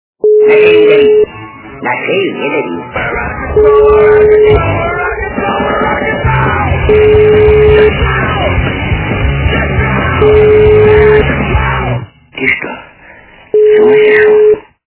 фильмы и телепередач
При заказе вы получаете реалтон без искажений.